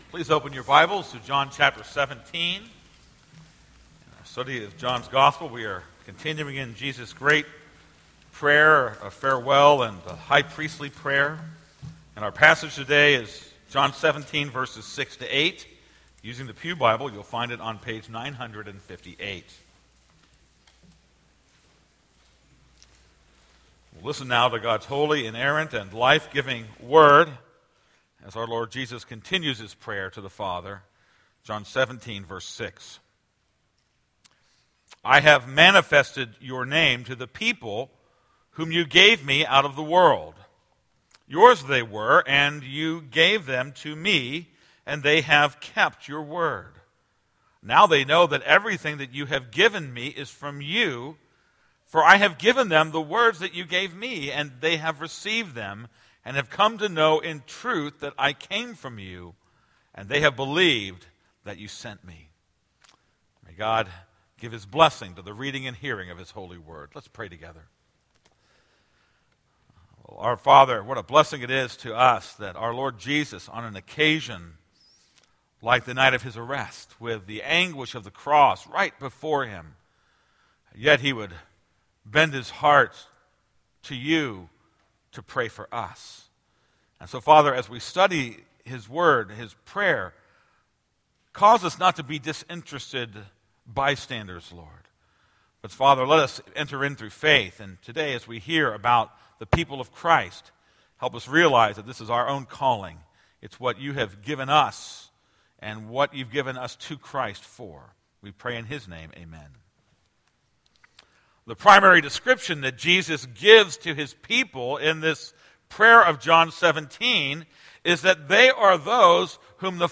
This is a sermon on John 17:6-8.